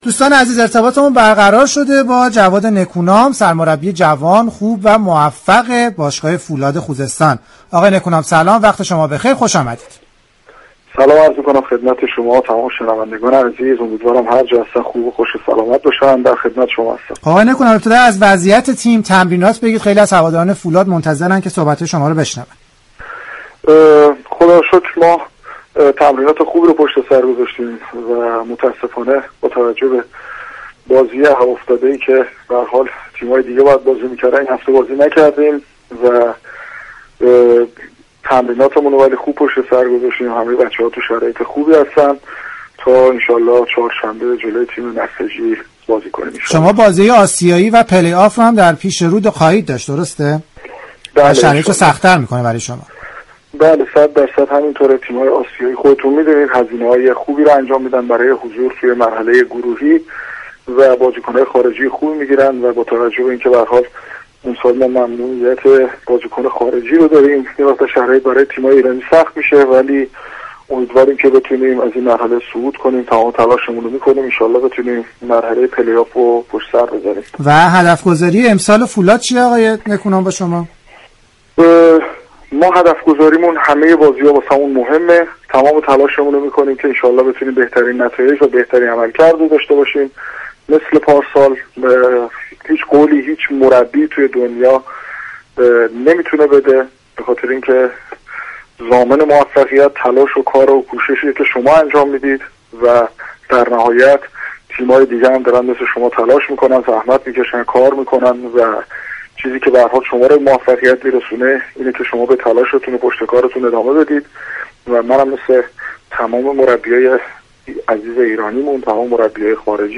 جواد نكونام در گفتگو با برنامه «از فوتبال چه خبر» یكشنبه 7 دی به انتقاد از نحوه برنامه‌ریزی سازمان لیگ برای ادامه مسابقات پرداخت.